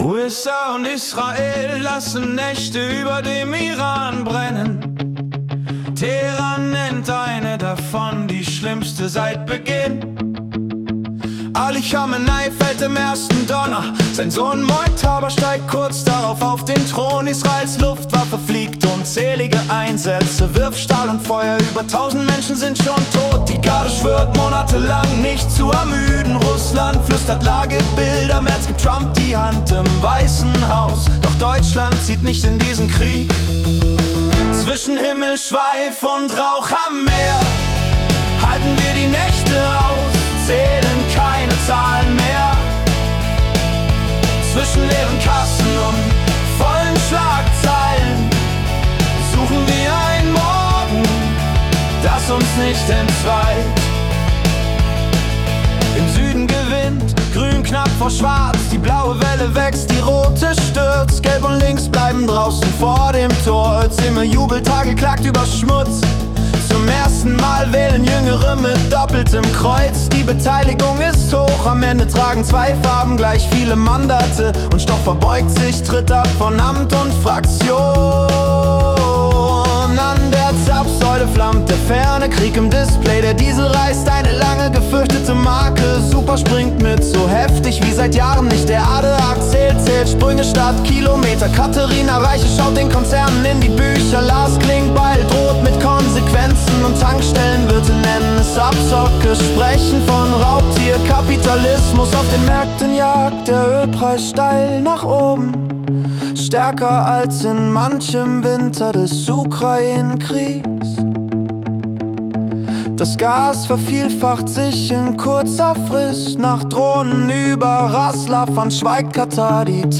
März 2026 als Singer-Songwriter-Song interpretiert.